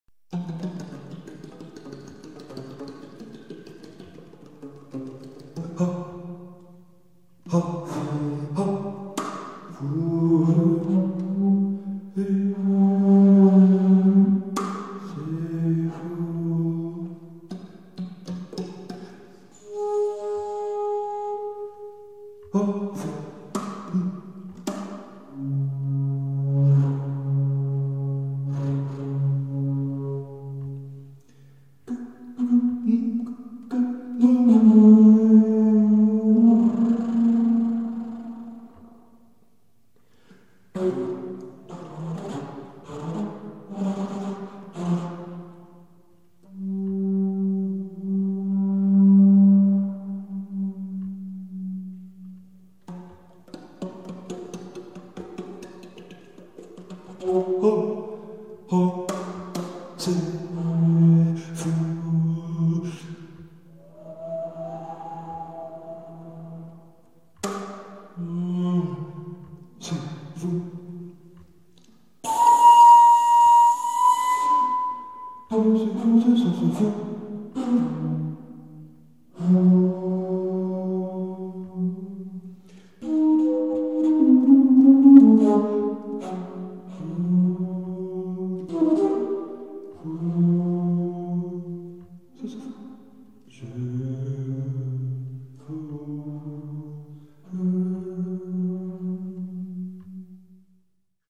Musique écrite
Flûte basse